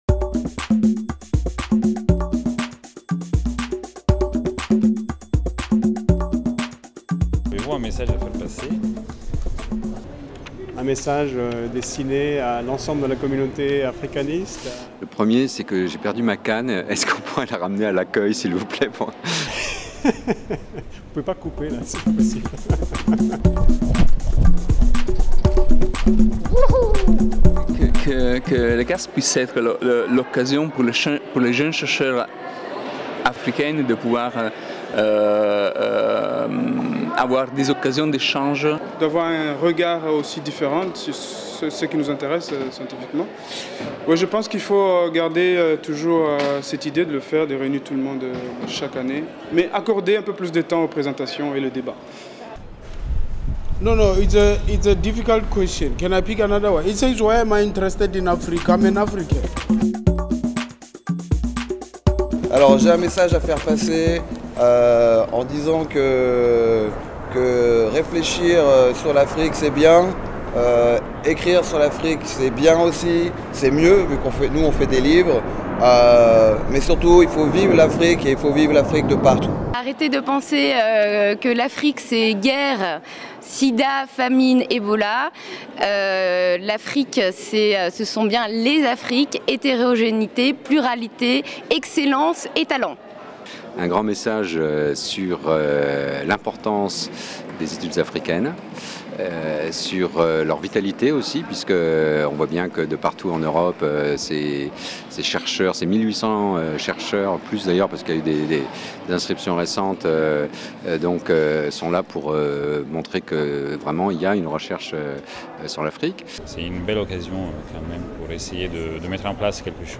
Micro-trottoir réalisé auprès des participants